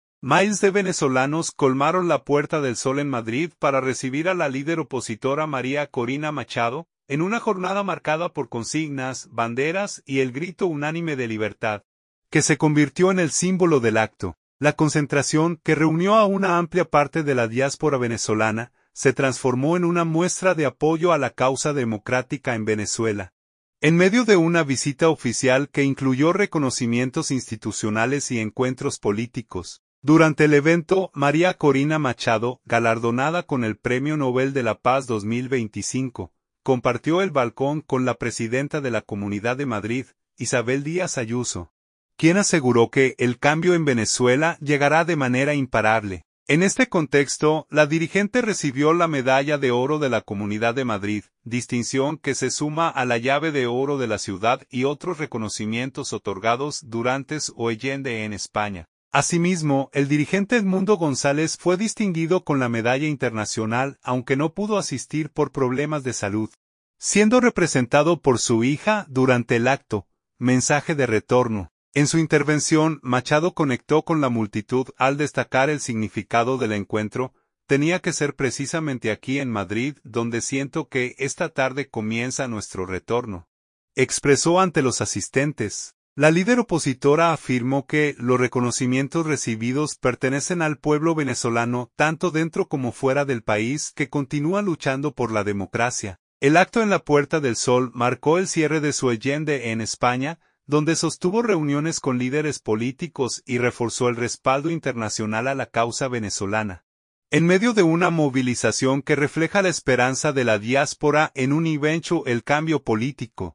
Miles de venezolanos colmaron la Puerta del Sol en Madrid para recibir a la líder opositora María Corina Machado, en una jornada marcada por consignas, banderas y el grito unánime de “Libertad”, que se convirtió en el símbolo del acto.